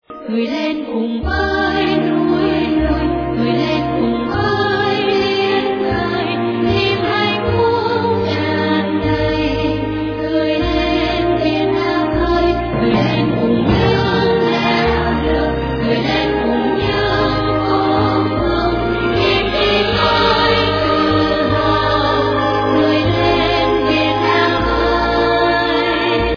Thể loại nhạc chuông: Nhạc trữ tình